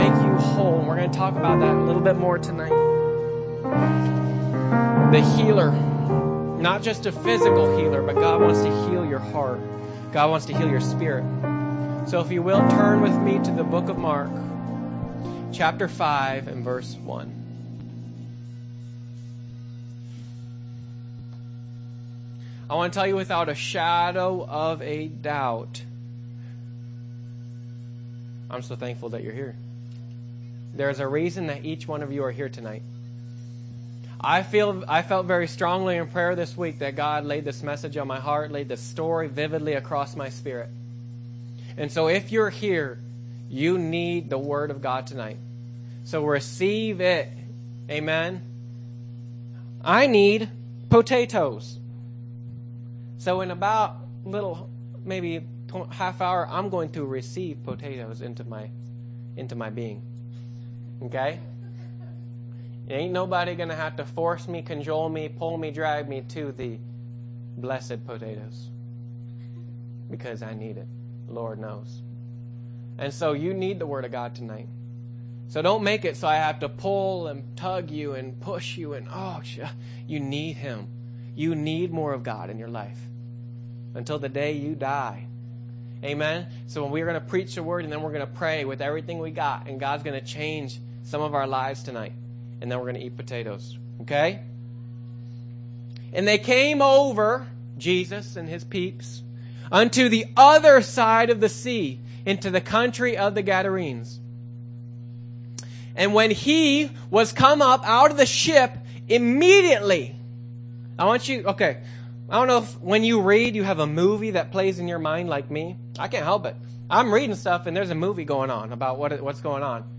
YOUTH SERVICE